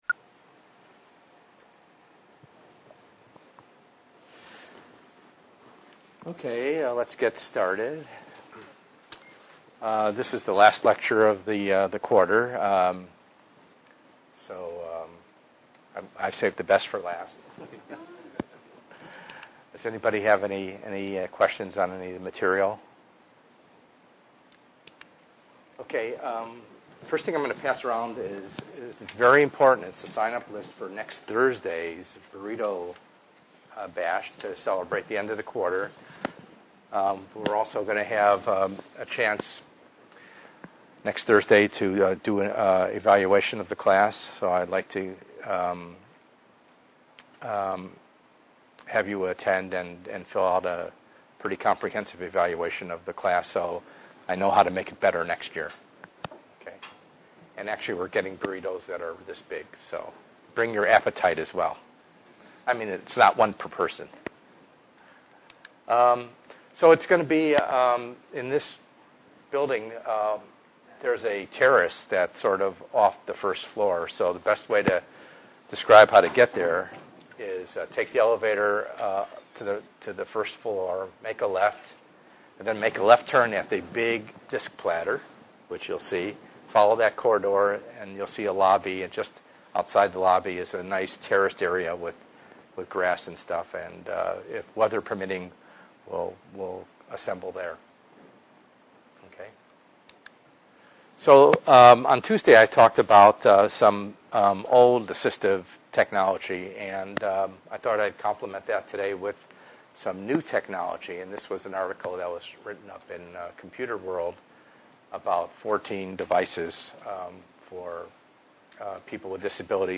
ENGR110/210: Perspectives in Assistive Technology - Lecture 9b